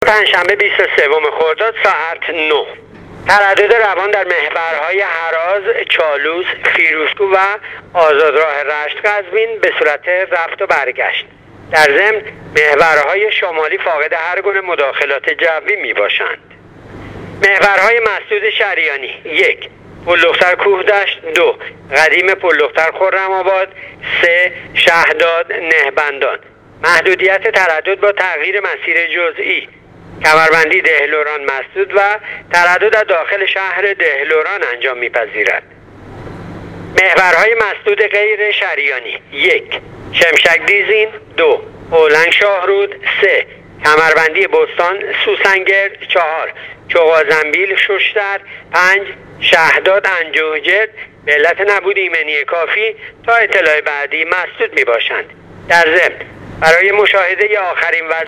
گزارش رادیو اینترنتی وزارت راه و شهرسازی از آخرین وضعیت‌ ترافیکی راه‌های کشور تا ساعت ۹ پنجشنبه ۲۳ خرداد/تردد روان در تمام محورهای شمالی بدون مداخلات جوی